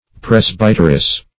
\Pres"by*ter*ess\